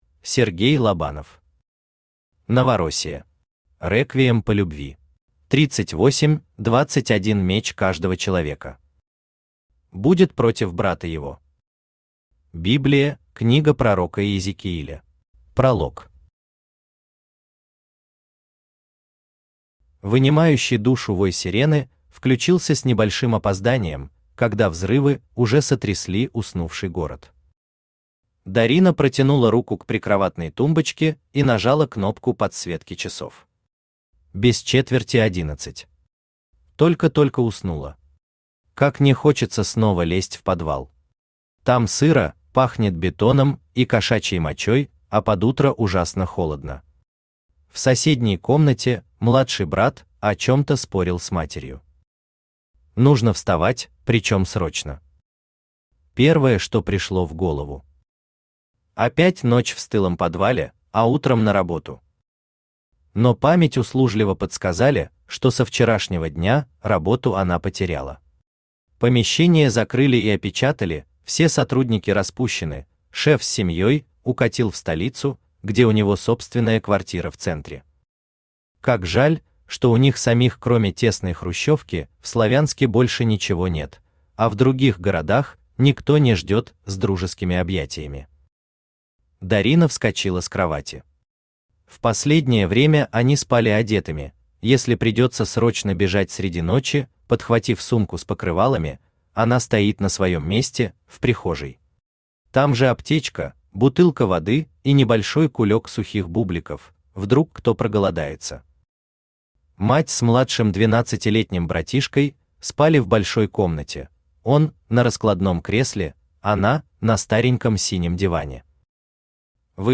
Аудиокнига Новороссия. Реквием по любви | Библиотека аудиокниг
Реквием по любви Автор Сергей Владимирович Лобанов Читает аудиокнигу Авточтец ЛитРес.